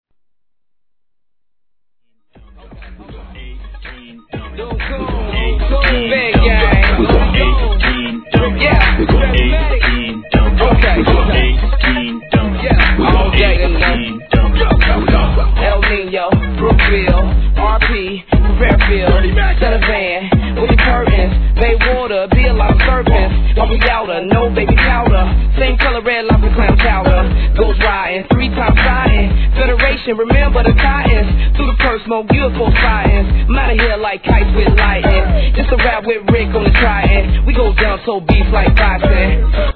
G-RAP/WEST COAST/SOUTH
(120BPM)